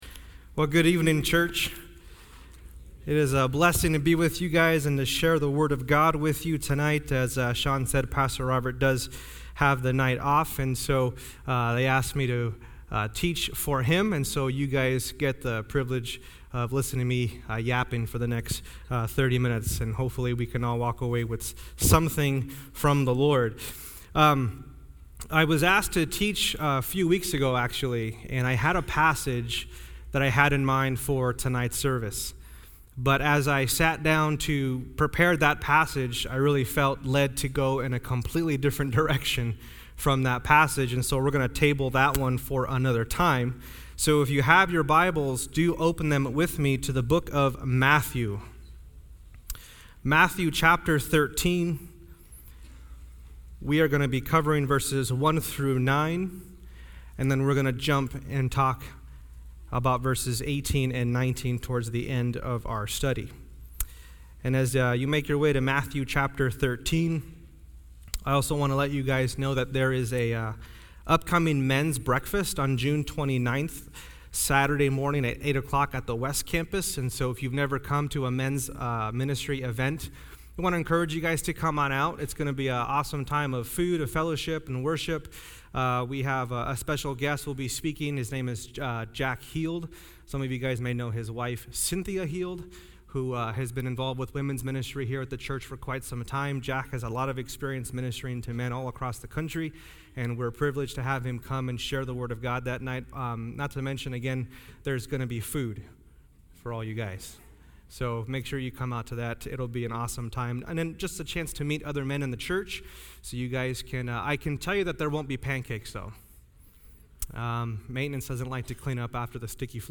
Guest speakers